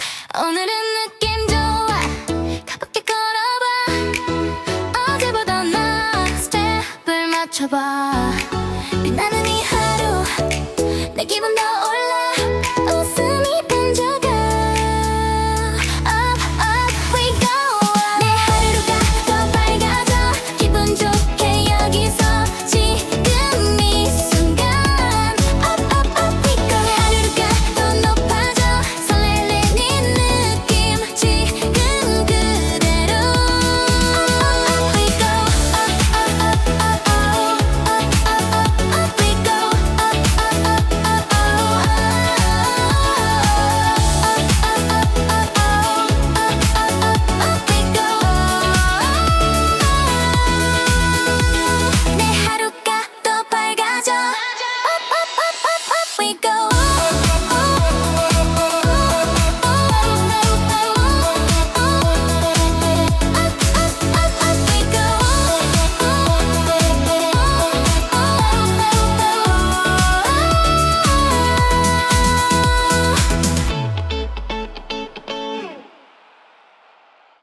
신나는 일렉트로 팝 만들어봤어요.